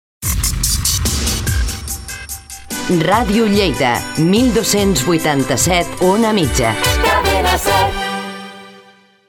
Identificació i freqüència